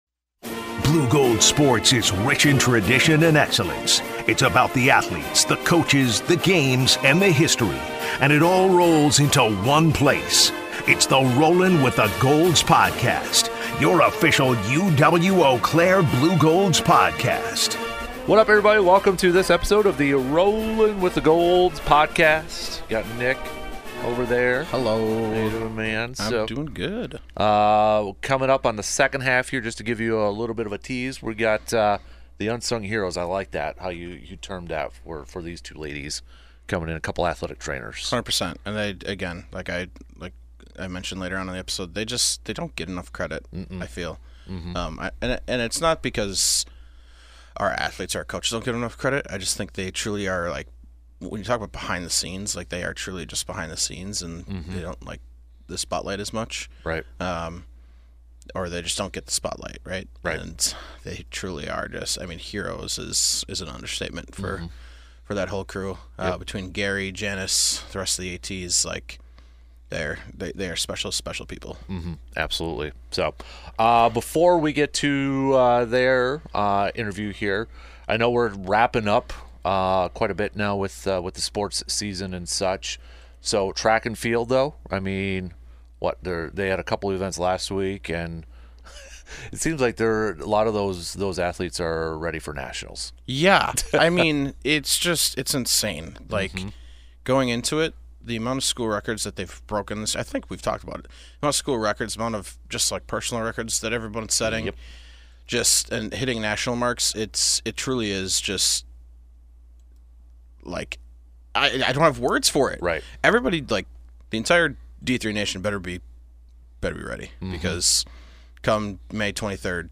Then, two unsung heroes in the athletic department make their appearance on the podcast. We welcome a couple of athletic trainers to the podcast to hear about what their typical schedule looks like, their future plans, and what it's like to work with student athletes and helping them get prepared for their games.